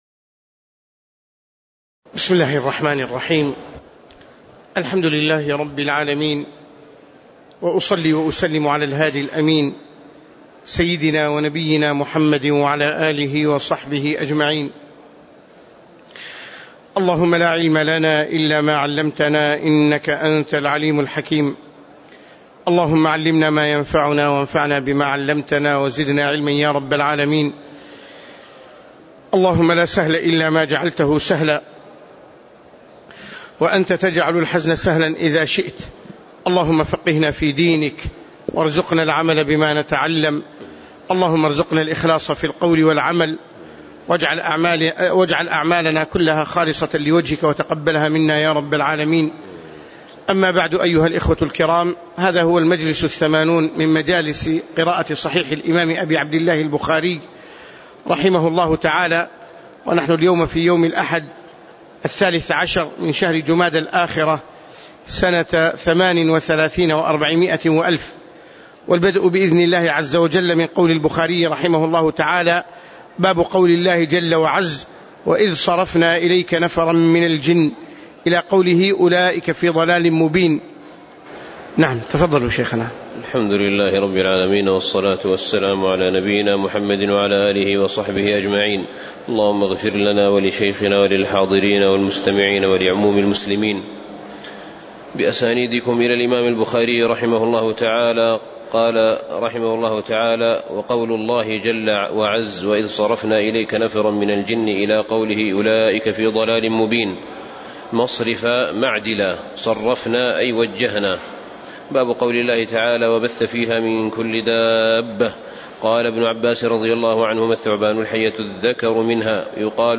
تاريخ النشر ١٣ جمادى الآخرة ١٤٣٨ هـ المكان: المسجد النبوي الشيخ